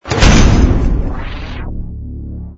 ui_equip_mount04.wav